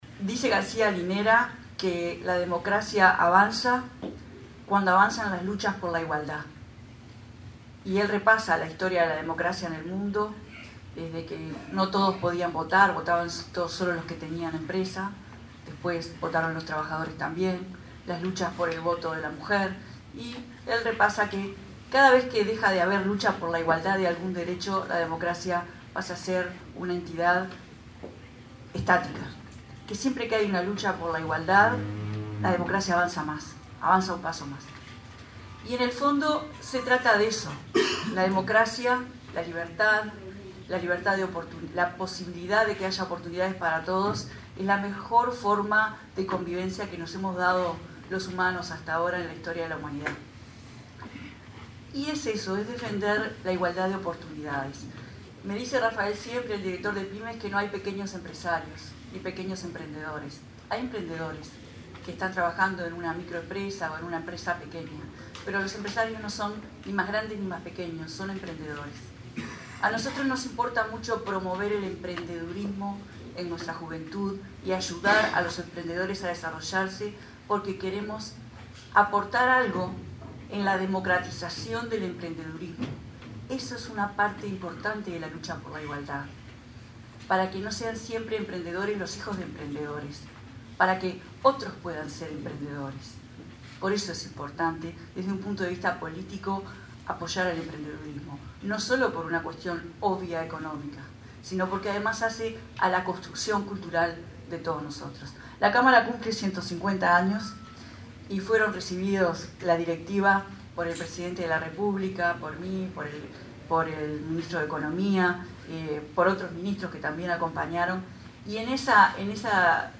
Así lo manifestó la ministra Carolina Cosse en la apertura de la feria de beneficios “Oportunidades para crecer” que se realiza en Montevideo.